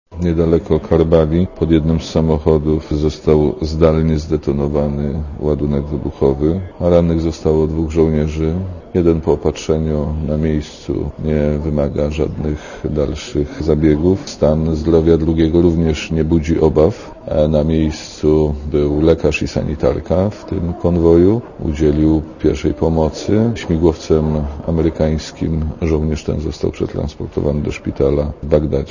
Dla Radia Zet mówi minister Szmajdziński (112 KB)